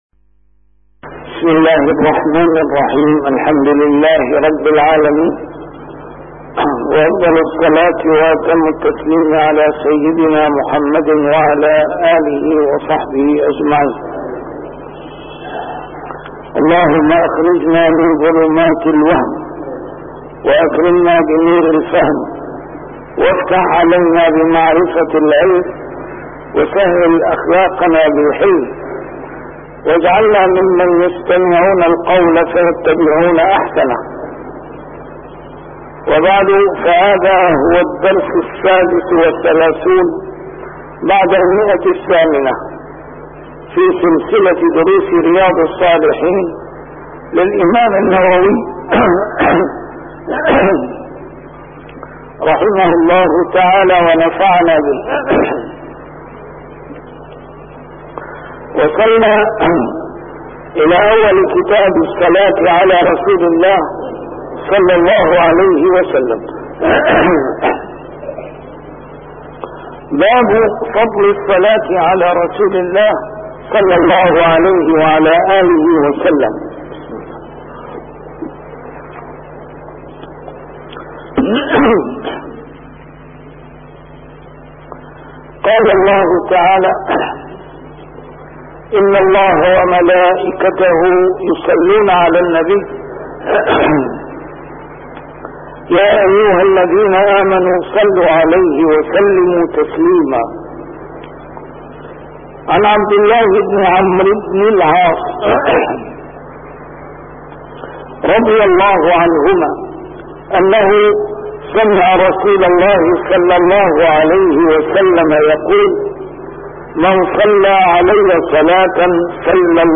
A MARTYR SCHOLAR: IMAM MUHAMMAD SAEED RAMADAN AL-BOUTI - الدروس العلمية - شرح كتاب رياض الصالحين - 836 - شرح رياض الصالحين: فضل الصلاة على رسول الله